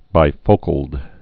(bī-fōkəld)